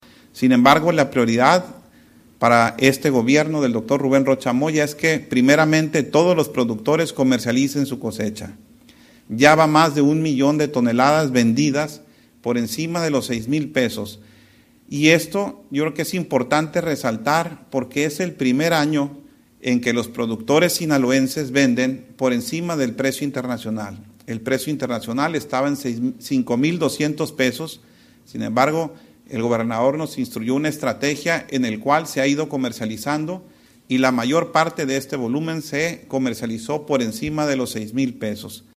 Culiacán, Sinaloa, a 21 de julio de 2025.- Durante su Conferencia Semanera del gobernador Rubén Rocha Moya, dio a conocer que hizo una propuesta a la presidenta de la República, Claudia Sheinbaum, para obtener un apoyo de la Federación y destinarlo a la comercialización de 400 mil toneladas de maíz que están pendientes de colocación, petición que encontró eco y por ello este martes el secretario de Agricultura, Ismael Bello Esquivel acudirá a la Ciudad de México para darle seguimiento a esta propuesta en la SADER.